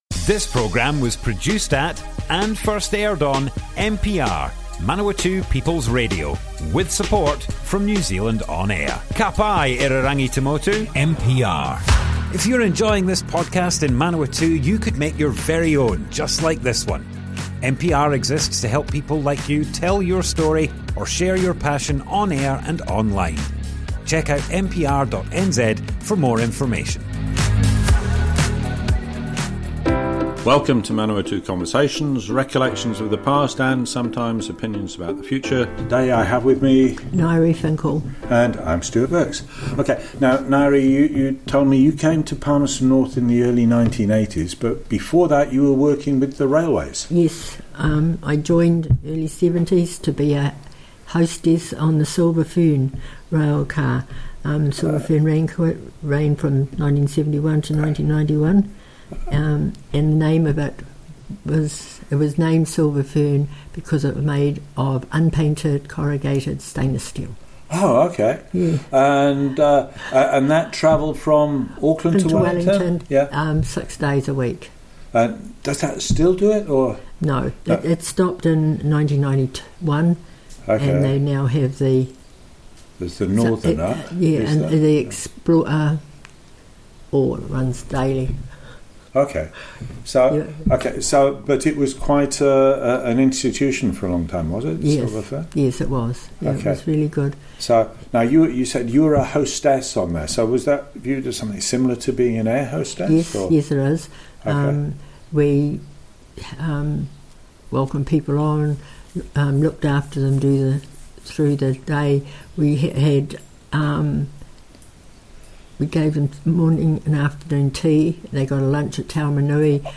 (Dog noises in the background.)